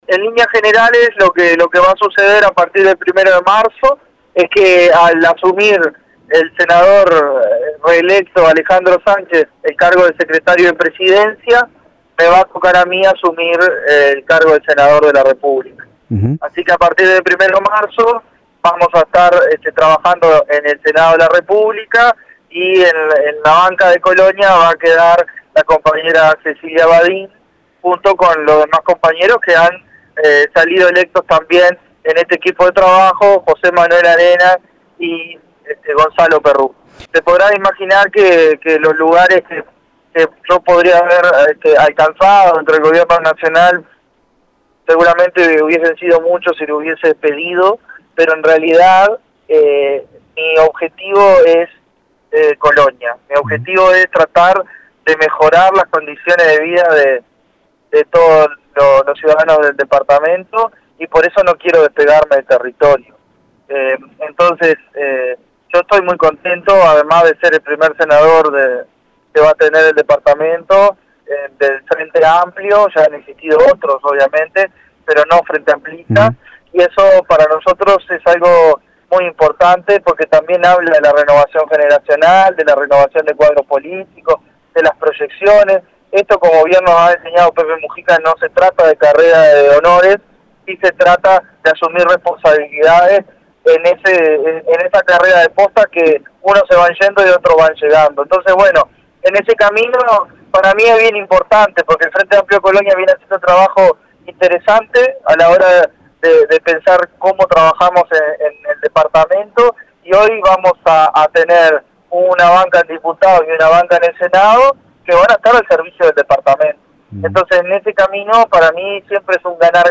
En diálogo con RO Contenidos, Vera dijo que no aspira a ningún cargo de gobierno porque quiere trabajar para Colonia y desde el territorio.